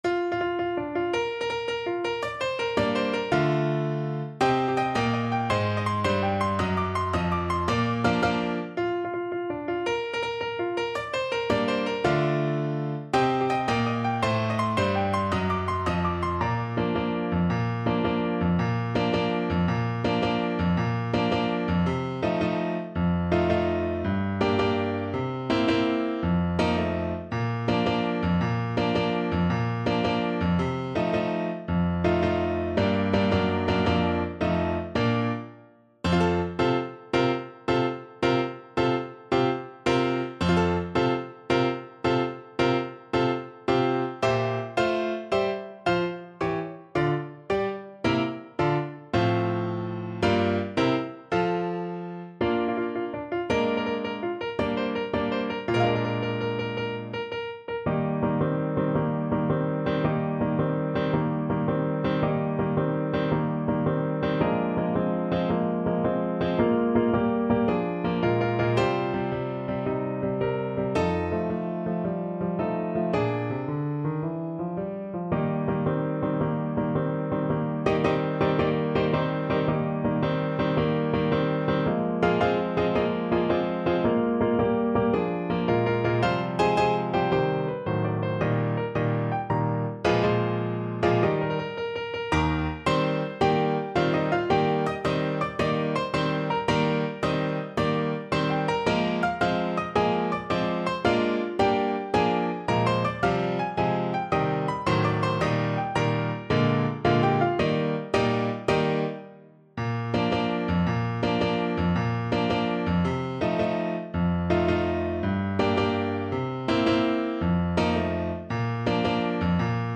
6/8 (View more 6/8 Music)
March .=c.110